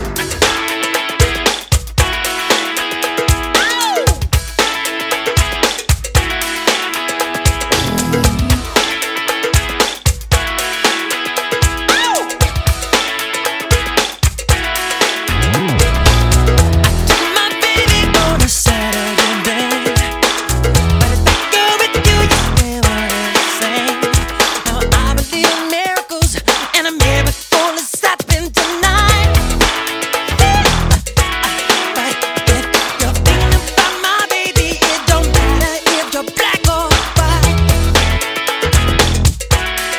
танцевальные , фанк , поп